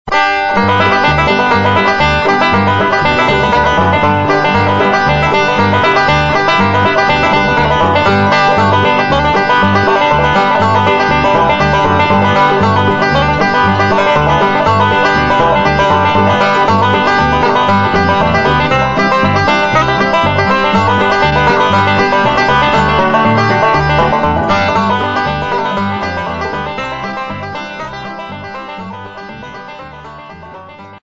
5-String Banjo .mp3 Samples
5-string Banjo Samples - Advanced Level